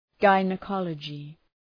Shkrimi fonetik{,gaınə’kɒlədʒı}
gynaecology.mp3